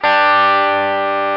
Guitar Th Sound Effect
guitar-th.mp3